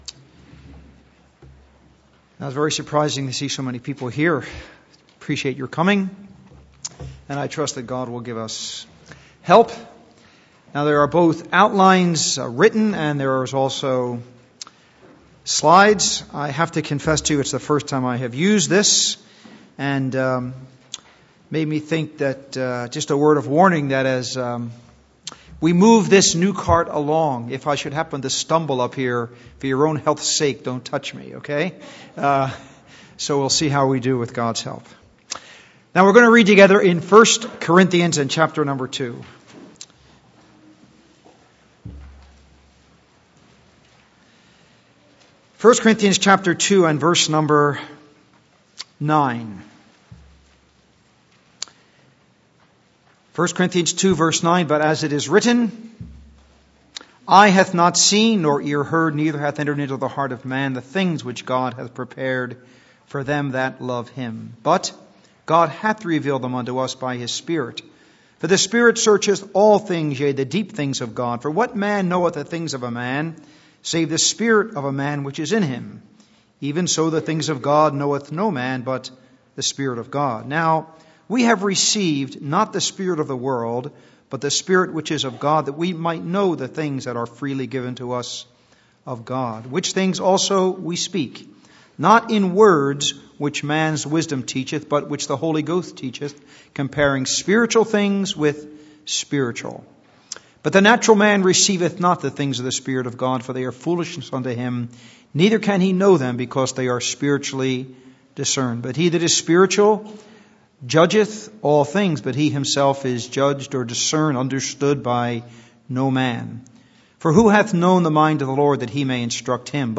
Series: How to Study Your Bible Service Type: Ministry